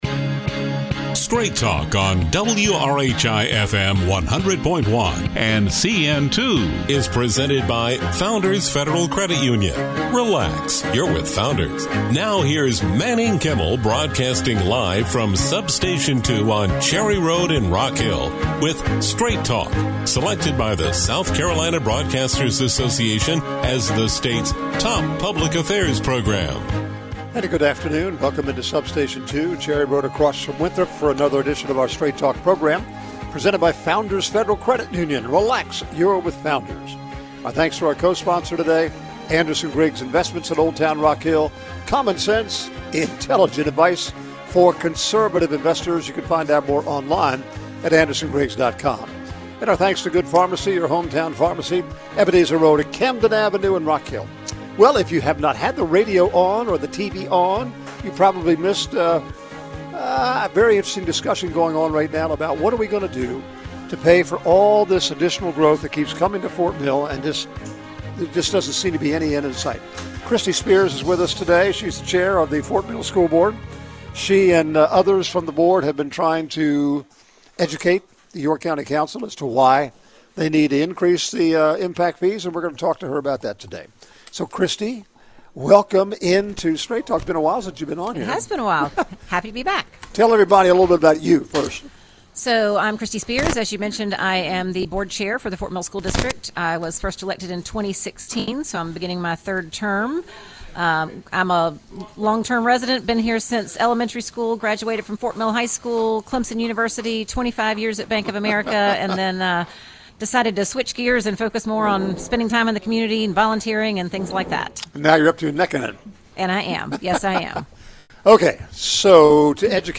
Straight Talk 03-19-25: Kristy Spears, Fort Mill School Board Chair – Impact fees